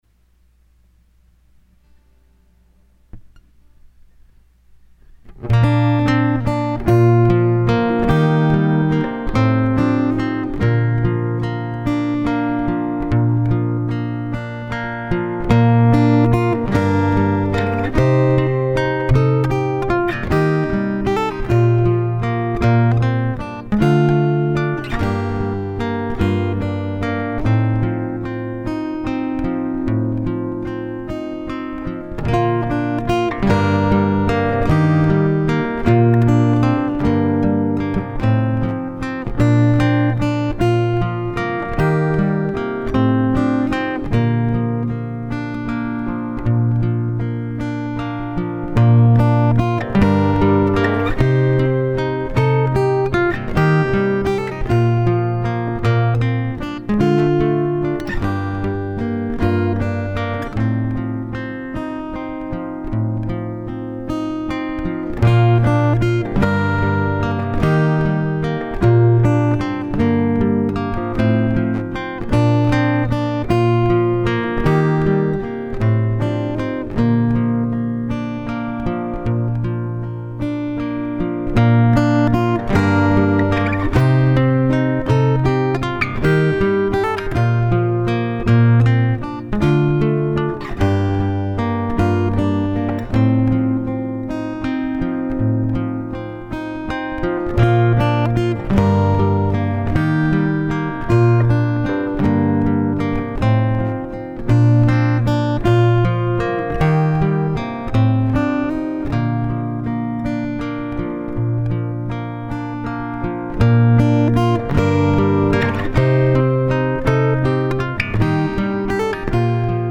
Vocals and music recorded live
Solo fingerstyle guitar.